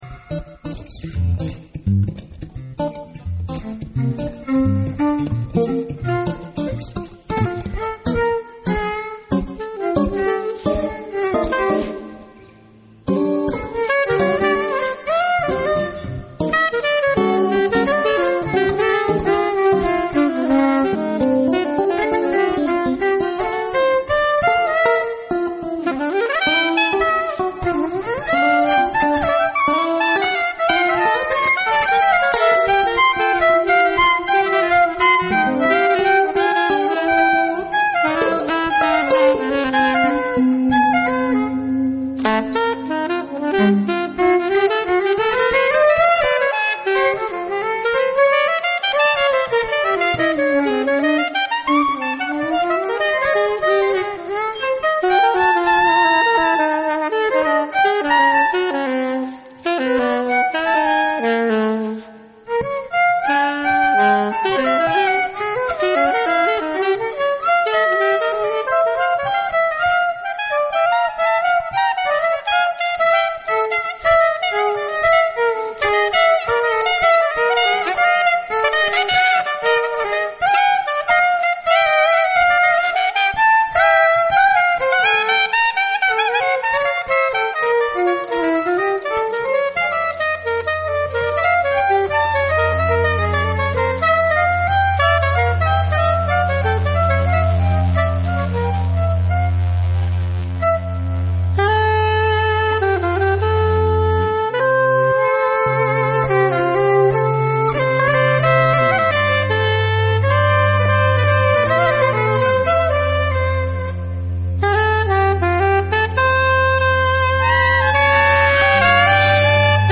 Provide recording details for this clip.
Genova, Galleria d'Arte Moderna, May 17 2008